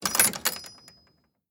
Gate Open.ogg